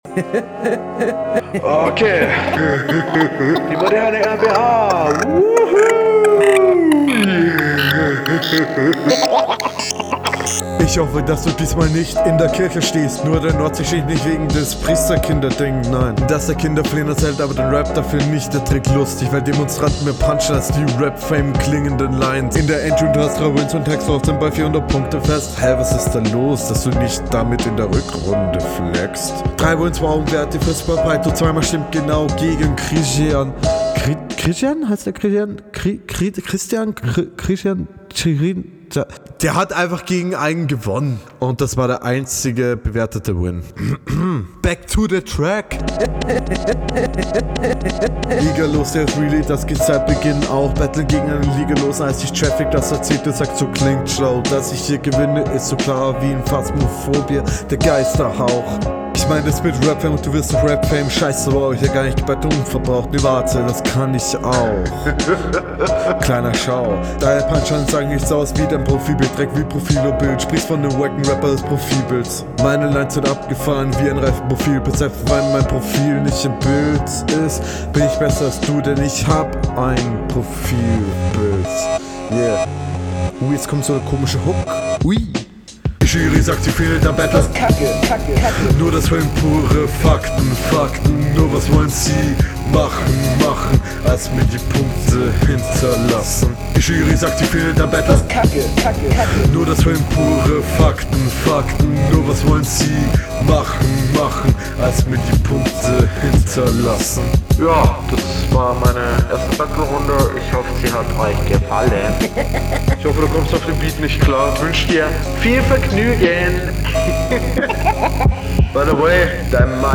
Flow ist noch sehr sehr holprig, verhaspelst dich immer mal wieder.
Du bist durchgehend offbeat und hast keinen wirklichen Flow.